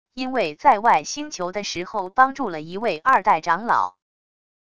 因为在外星球的时候帮助了一位二代长老wav音频生成系统WAV Audio Player